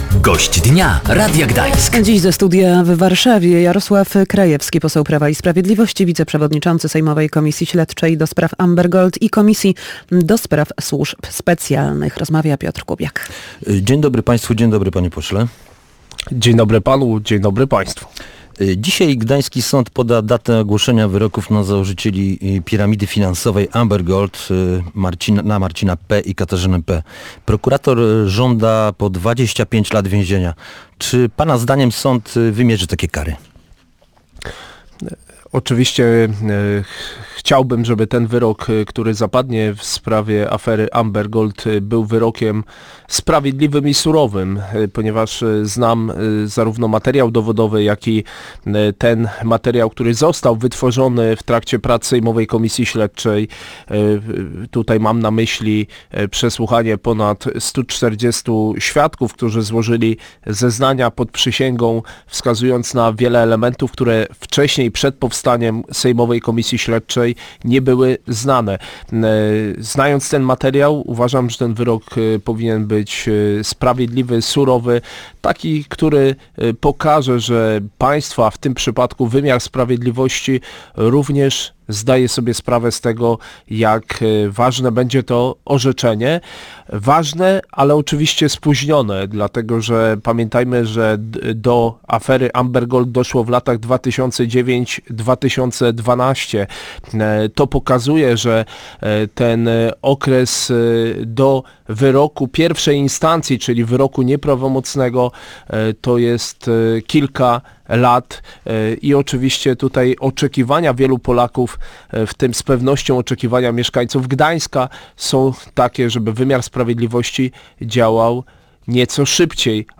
Gościem Radia Gdańsk był Jarosław Krajewski, poseł Prawa i Sprawiedliwości, wiceprzewodniczący sejmowej komisji śledczej ds. Amber Gold i komisji do spraw Służb Specjalnych.